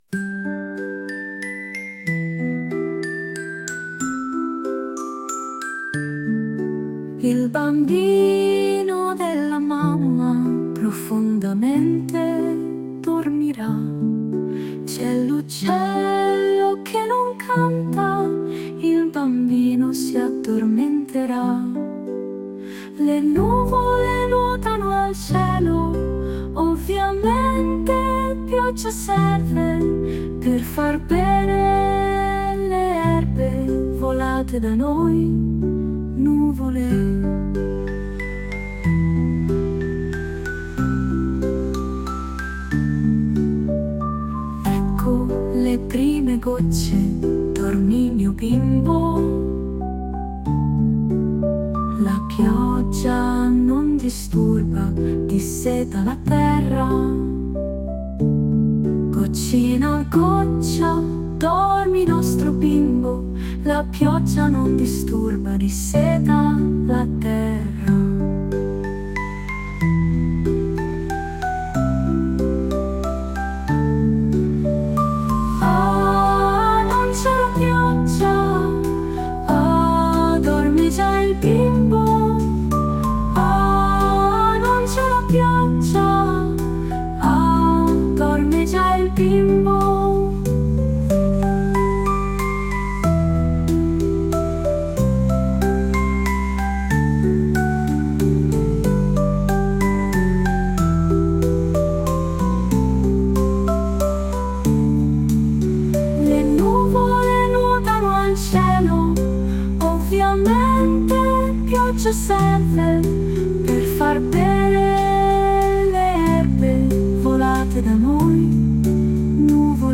Музичний супровід створено за допомогою SUNO AI
СТИЛЬОВІ ЖАНРИ: Ліричний
12 12 12 Така ніжна колискова! 42 43 hi